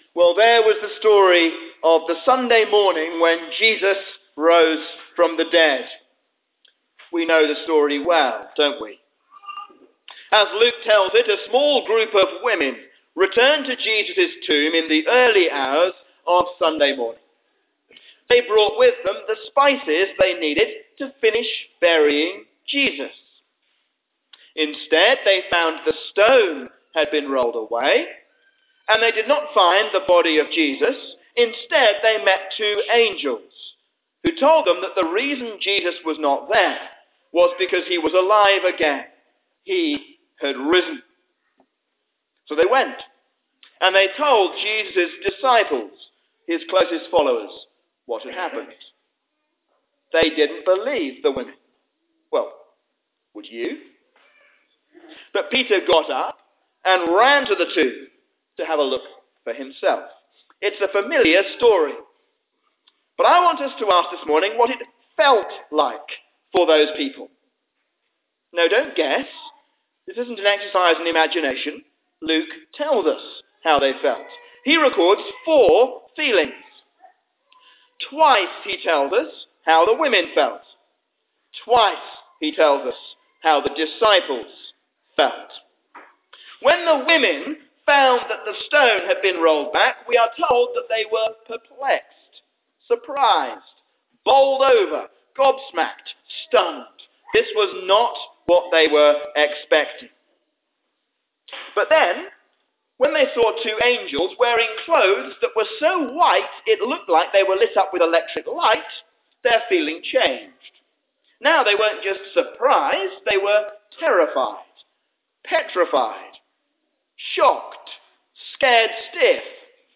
A sermon on Luke 24:1-12